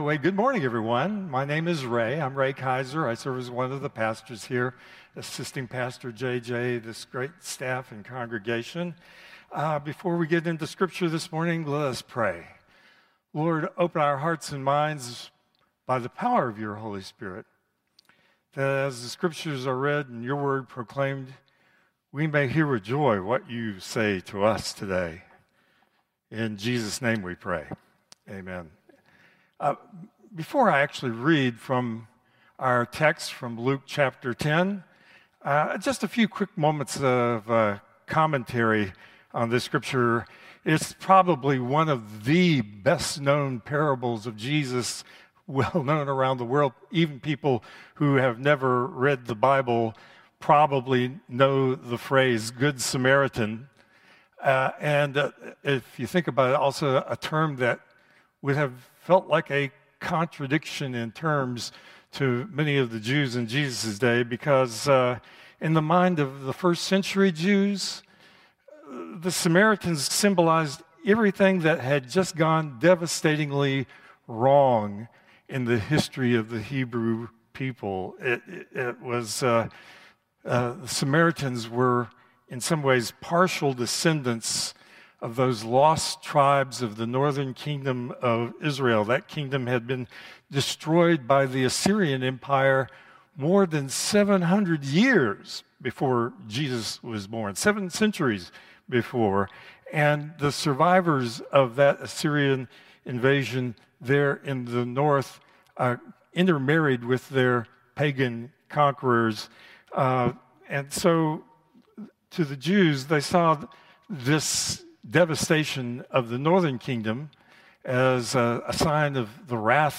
Contemporary Service 3/23/2025